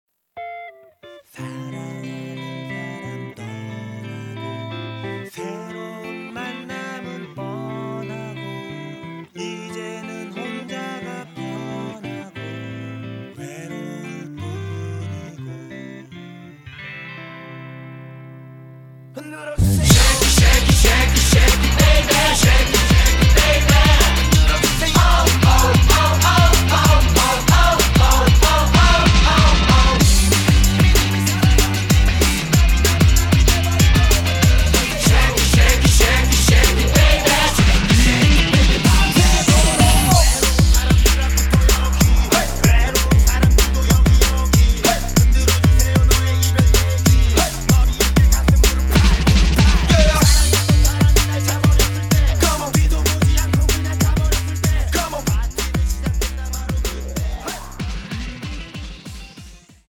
음정 원키 3:19
장르 가요 구분 Voice MR